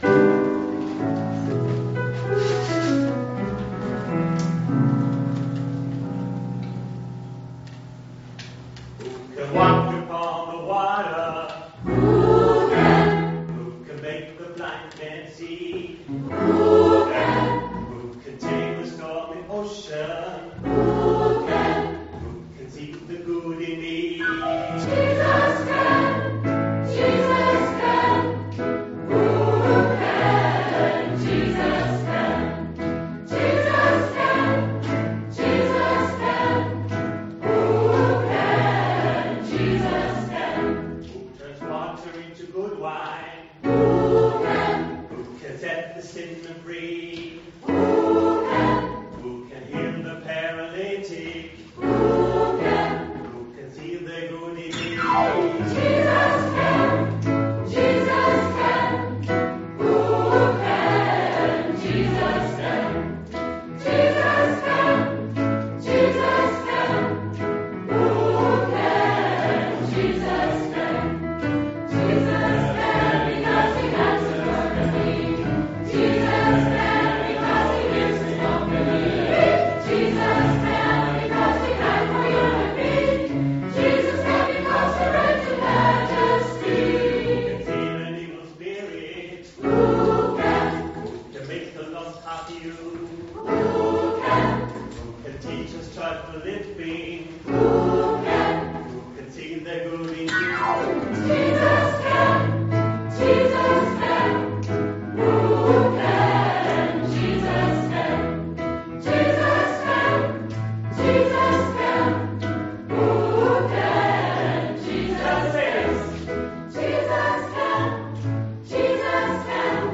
Gospelchor der Gnadenkirche Wien
Jesus can - Aufnahme aus der Gnadenkirche, Juni 2016 (1,46 mb)